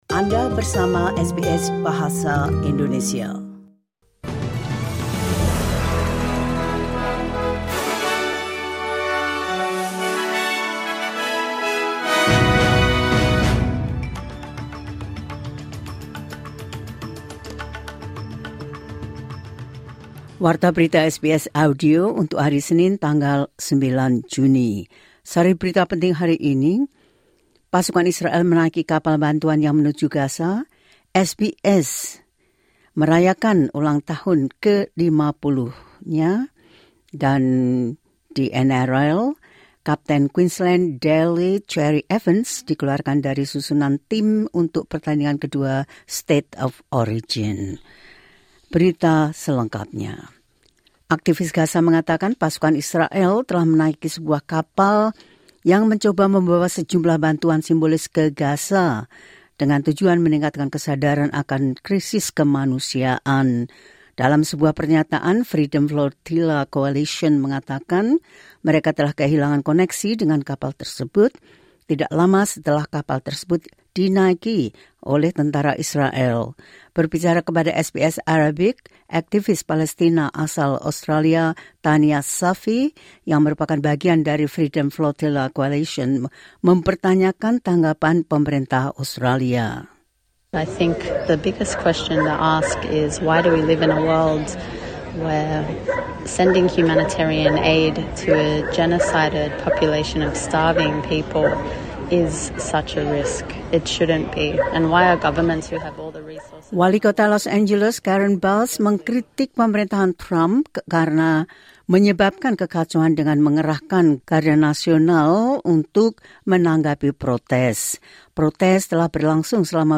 Berita terkini SBS Audio Program Bahasa Indonesia – 09 Jun 2025
The latest news SBS Audio Indonesian Program – 09 Jun 2025.